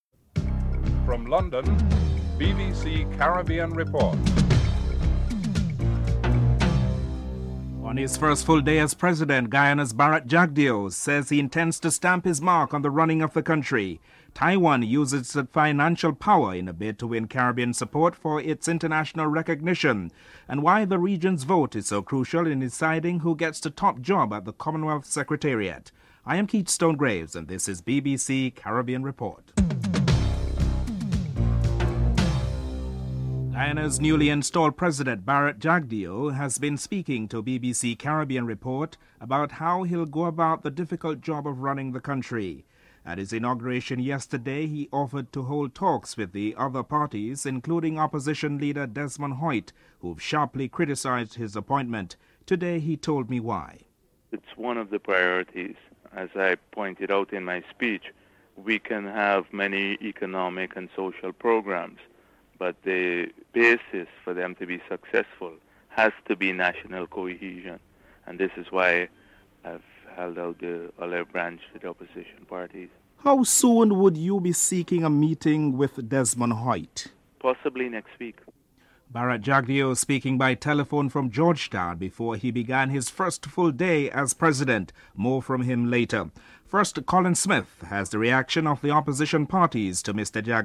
Guyana’s newly installed president Bharrat Jagdeo speaks to BBC Caribbean Report on how he plans to govern the country.